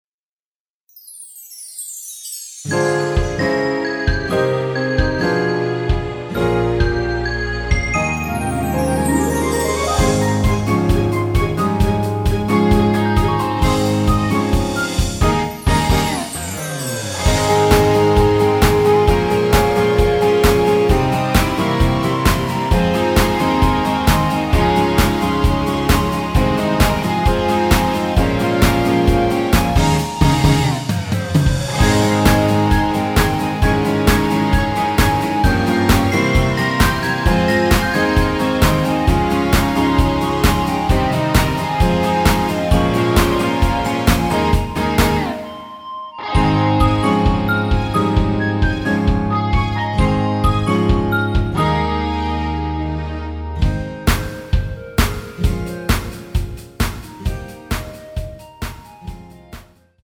Ab
노래방에서 노래를 부르실때 노래 부분에 가이드 멜로디가 따라 나와서
앞부분30초, 뒷부분30초씩 편집해서 올려 드리고 있습니다.
중간에 음이 끈어지고 다시 나오는 이유는